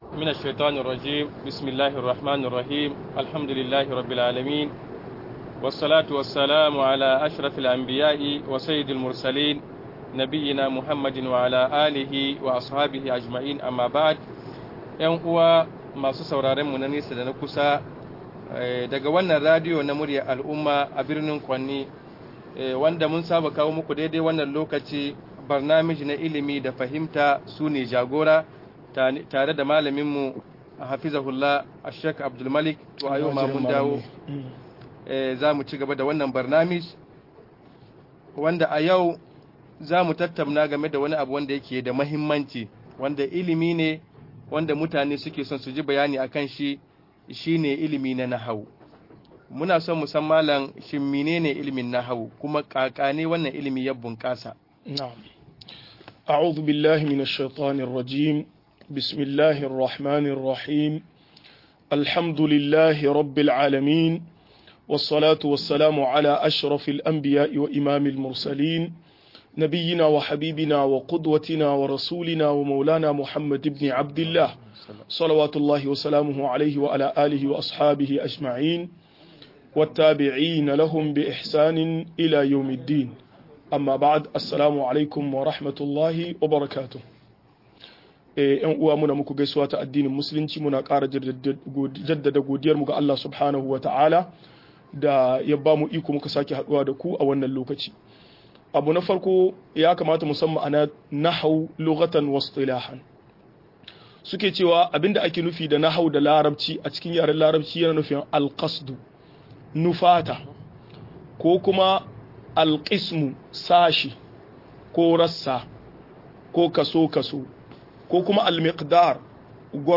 Nahawu da alakar sa da shari'a - MUHADARA